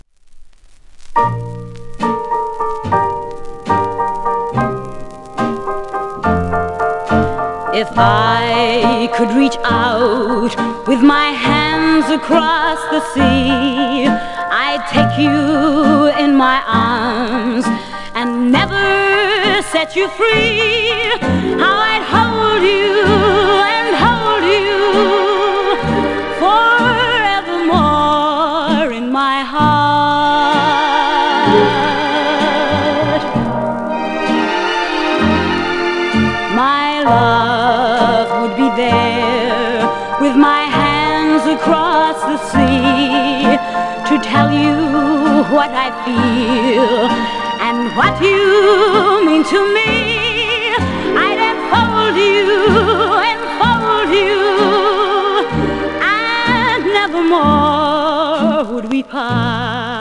1958年録音